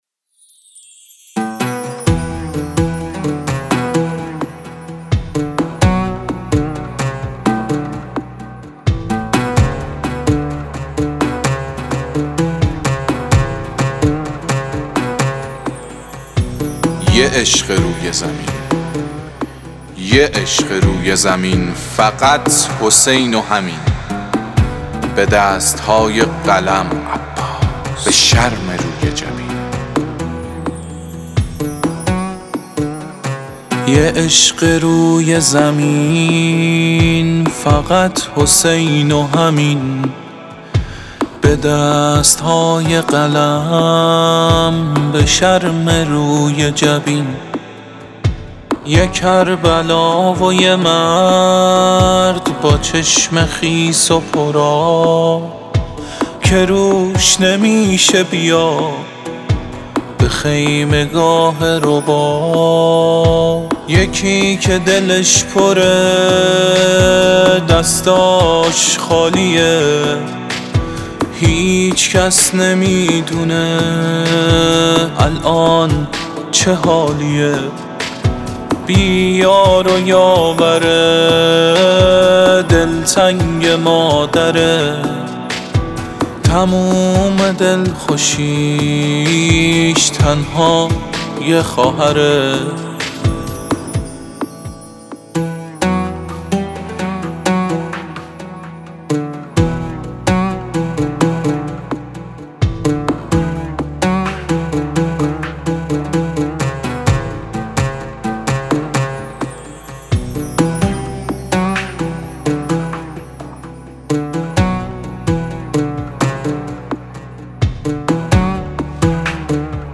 دانلود مداحی